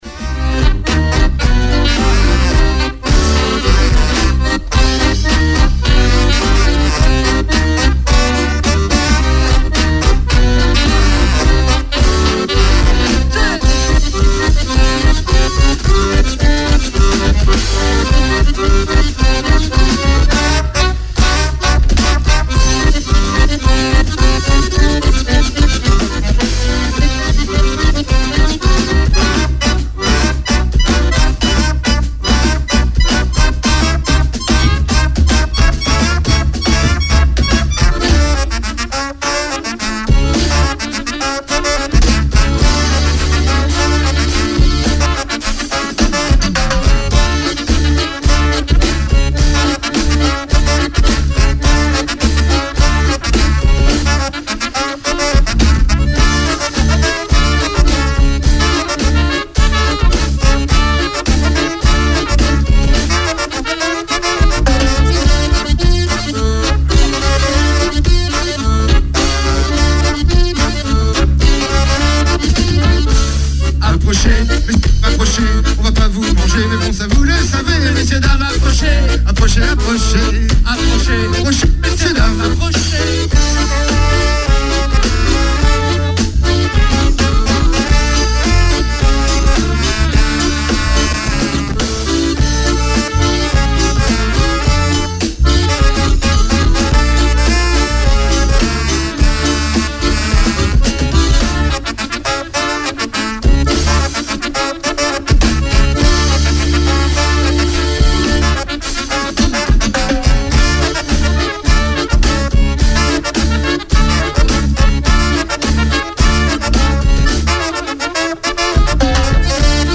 Chansons festives